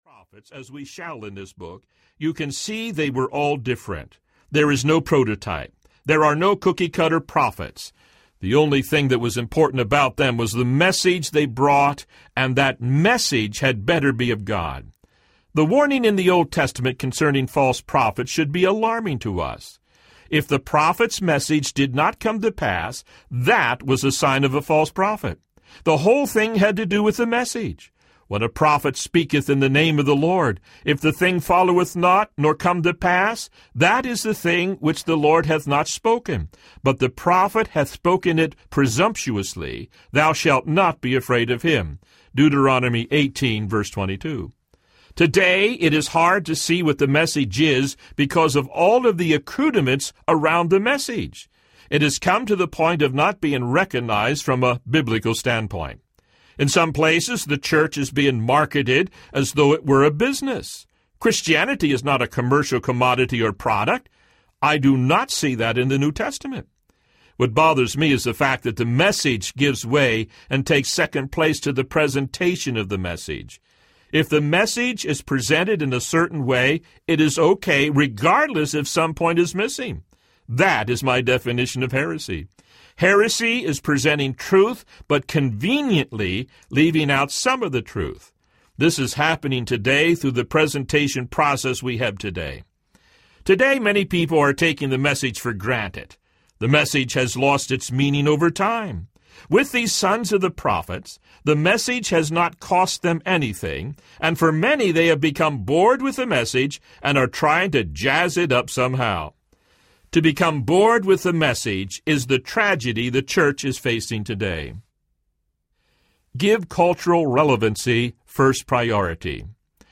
Voice of a Prophet Audiobook
Narrator
5.2 Hrs. – Unabridged